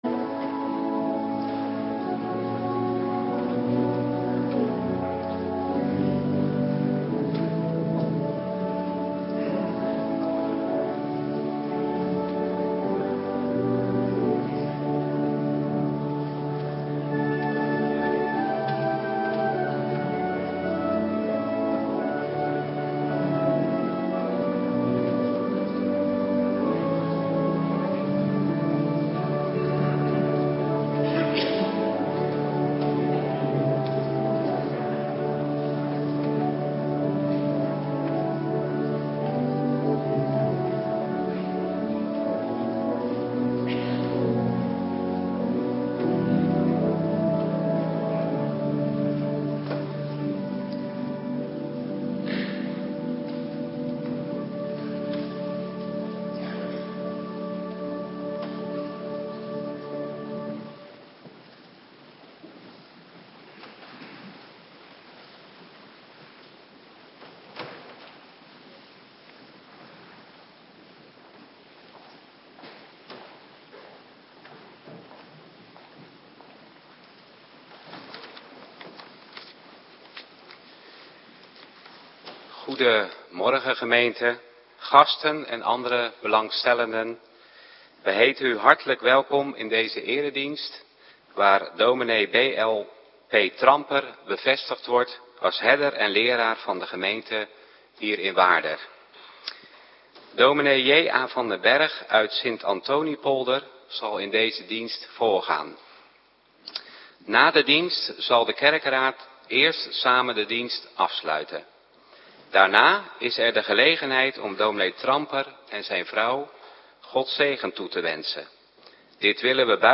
Morgendienst bevestigingsdienst - Cluster A
Locatie: Hervormde Gemeente Waarder